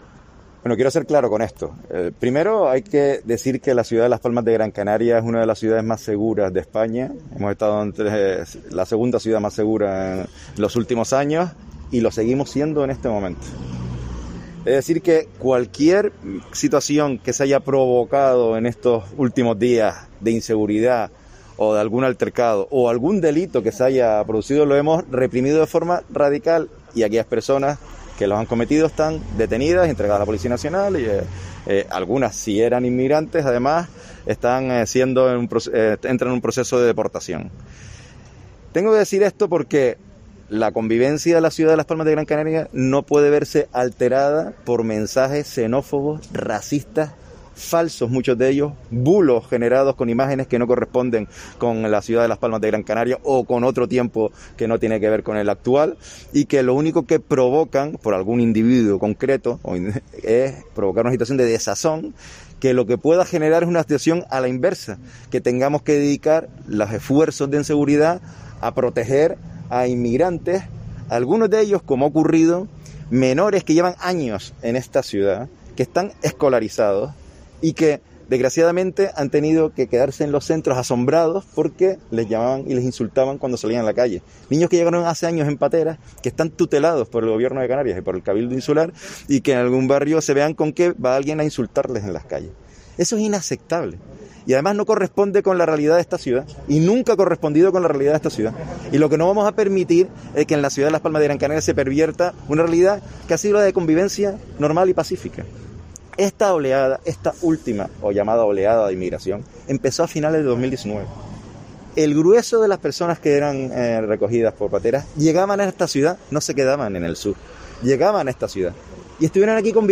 Declaraciones a los medios de comunicación de Augusto Hidalgo, alcalde de Las Palmas de Gran Canaria